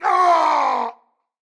PlayerFall.ogg